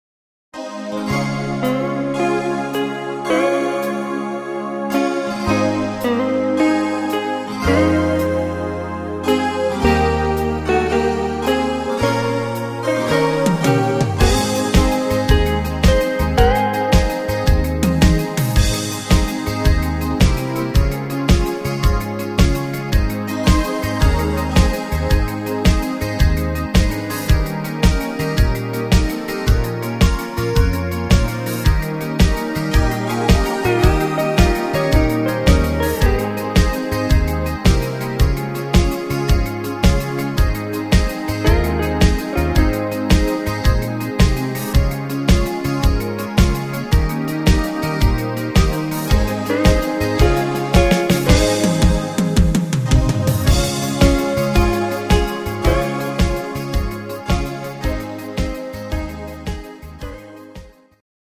instr. Hawaiigitarre